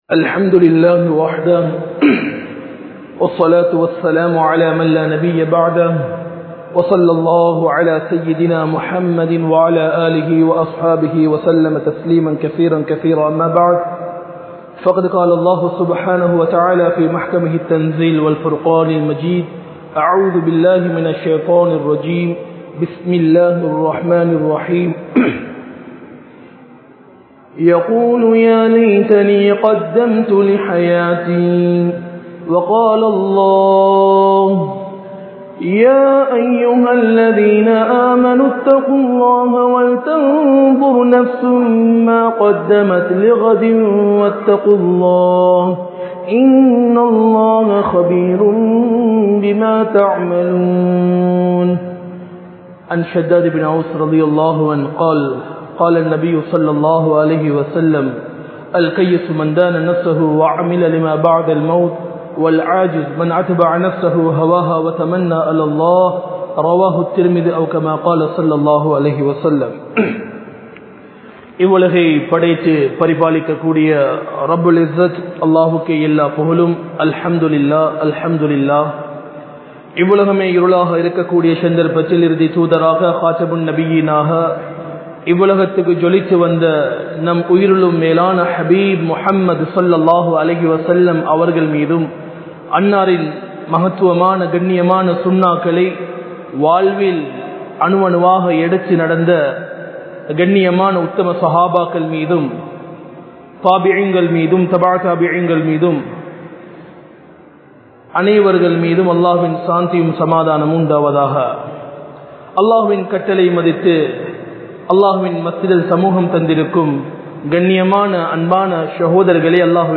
Manithanidamulla 03 Vahaiyana Ennangal (மனிதனிடமுள்ள 03 வகையான எண்ணங்கள்) | Audio Bayans | All Ceylon Muslim Youth Community | Addalaichenai
Panadura, Pallimulla Jumua Masjith